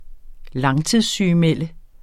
Udtale [ -ˌmεlˀə ]